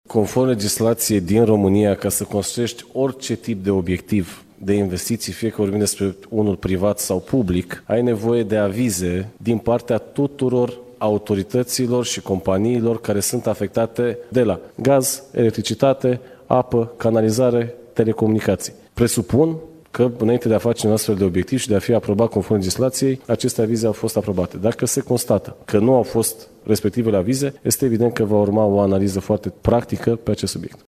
Primarul Sectorului 3, Robert Negoiță, este anchetat de procurorii Direcției Naționale Anticorupție într-un dosar privind construirea ilegală a unui drum peste conducte de gaz. În cadrul unei conferințe de presă la sediul PSD, ministrul Energiei, Bogdan Ivan, a fost întrebat cum se poate rezolva situația: dacă acele străzi trebuie să dispară sau conductele de gaz trebuie amenajate altfel.